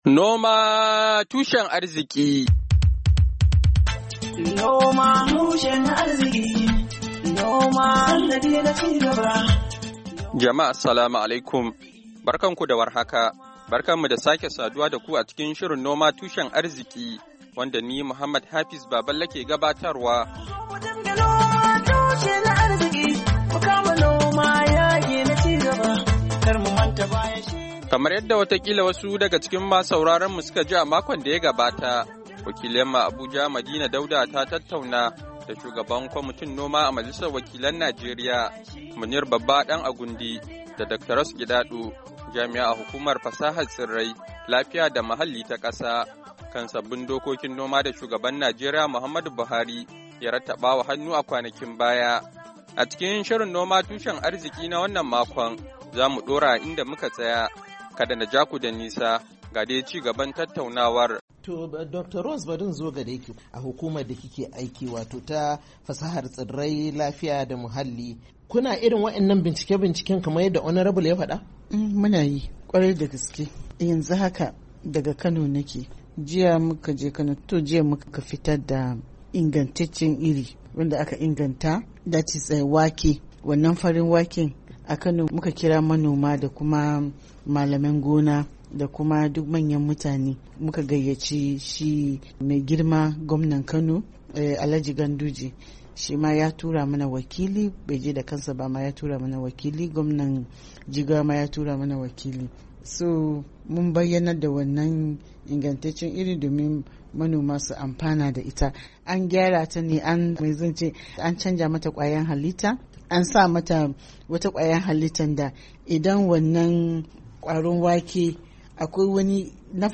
A cikin shirin noma tushen arziki na wannan makon, za mu kawo mu ku ci gaban tattauna wa da kwararru kan sabbin dokokin noma da Shugaban Najeriya, Muhammadu Buhari, ya rattaba wa hannu a yan makonnin da su ka gabata.